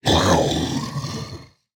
evil-rabbit-v2.ogg